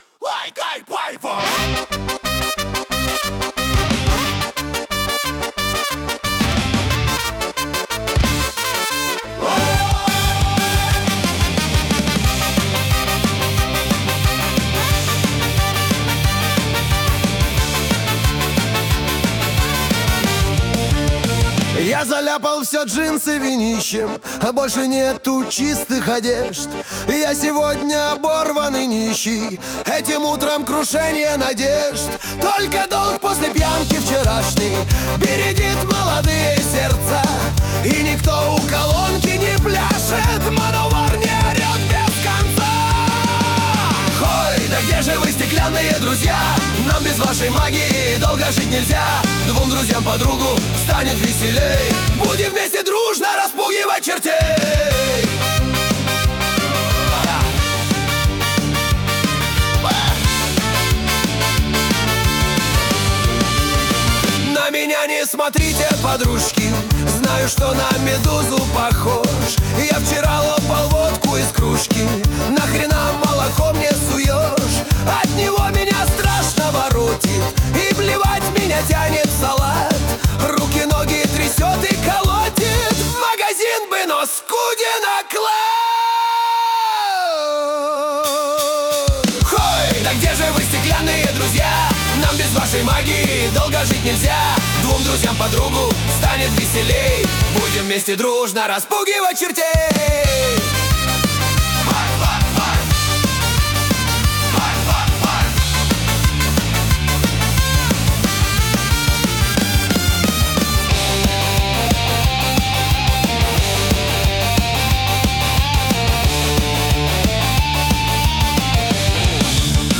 Второй - трек чисто поржать сделал ИИ, по инструкциям друга, недавно - получилось СКА в духе "Ленинграда", еще и шнуровским голосом.
Он веселый, исключительно качественный, но нет развития темы - все по накатанной тропе, даж тональность не меняется.
Исполнение веселое, динамичное, в нем не приходилось вычищать прорву наведенных шумов и свистов, поэтому запись невероятно качественная, но механическая. Даже с затягами во втором куплете, все равно робот, эмоции сухие в доску.
То, что сделал ИИ.